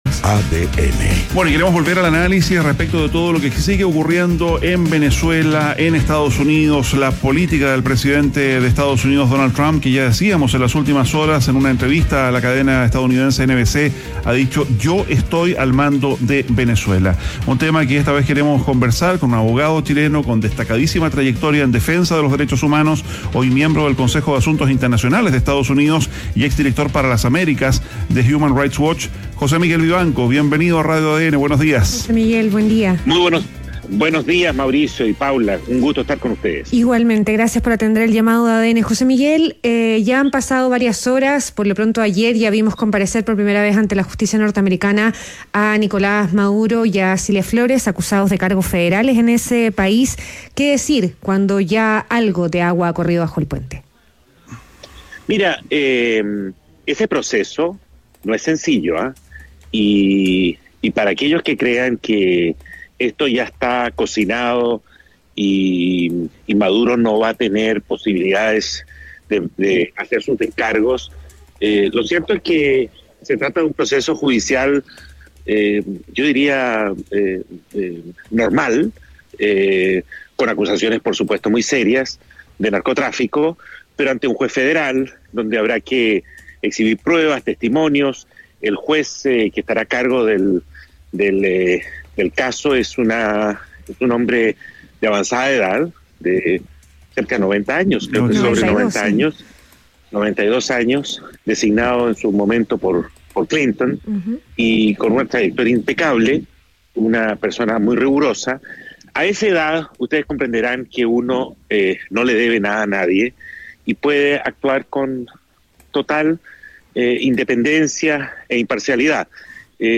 ADN Hoy - Entrevista a José Miguel Vivanco, miembro del Consejo de Asuntos Internacionales de EEUU y exdirector de Human Rights Watch